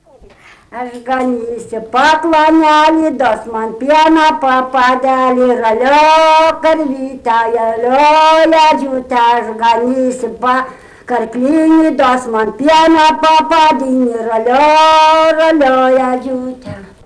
smulkieji žanrai
Ryžiškė
vokalinis